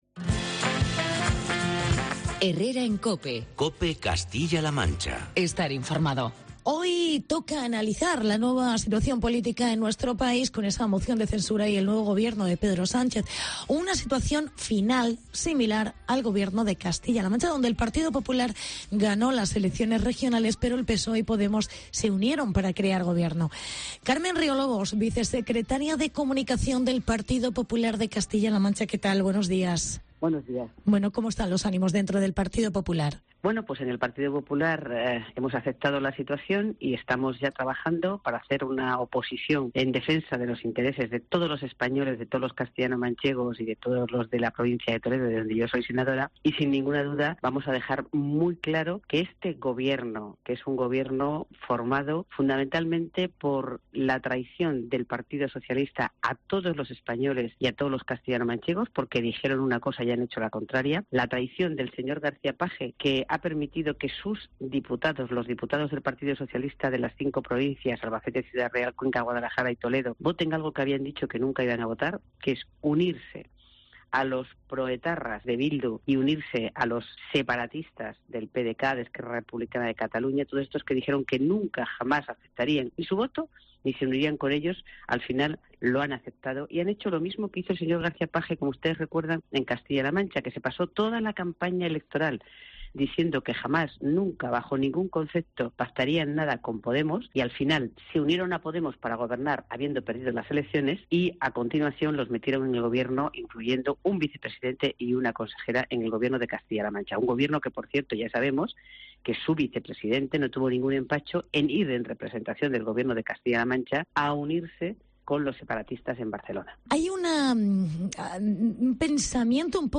Entrevista con Carmen Riolobos